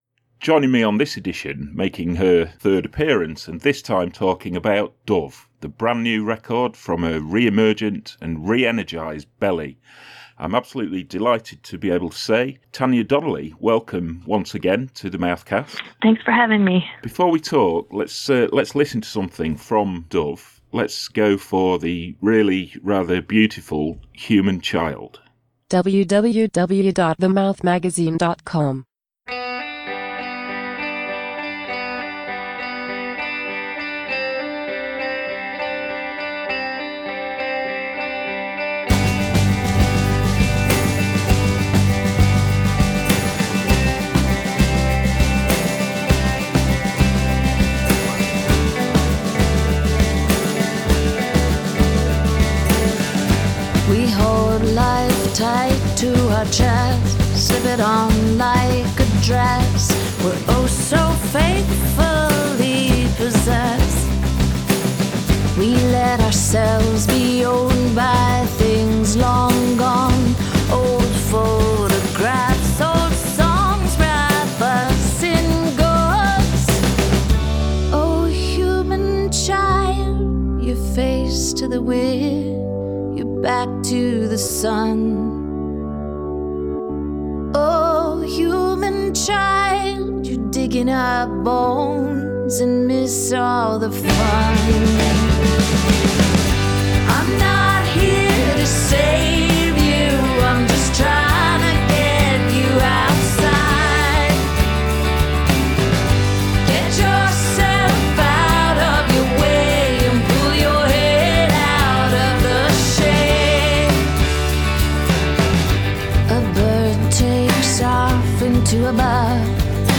In this edition of The Mouthcast, with Tanya talking from home in Boston, Massachusetts, we discuss the record; how it came to be, what it means and more…